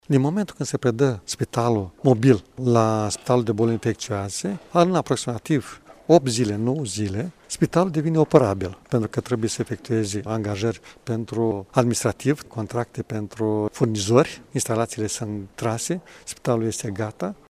Preşedintele Consiliului Județean Iaşi, Maricel Popa a declarat că spitalul poate deveni operaţional în maximum 10 zile, menționând că în acest moment spitale suport Covid de la Iaşi nu mai pot face internări.